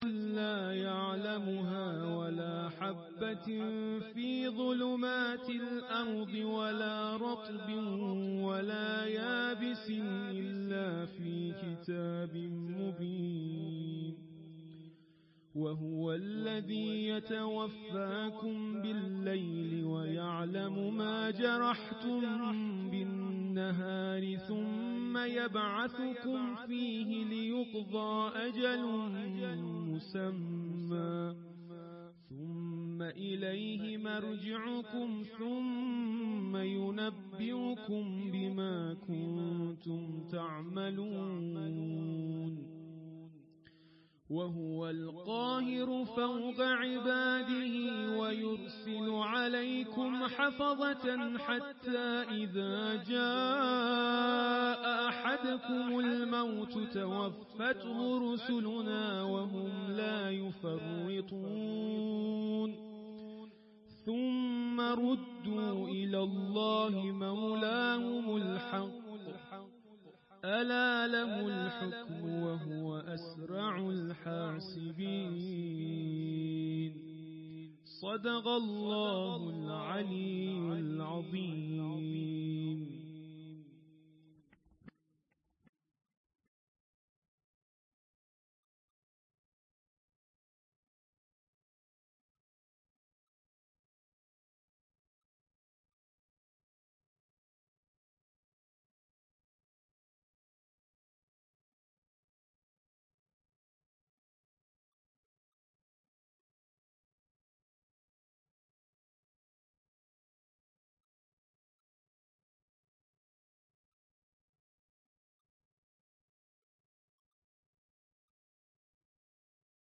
مراسم عزاداری شهادت حضرت امام موسی‌کاظم «علیه‌السلام»
در صحن مدرسه برگزار شد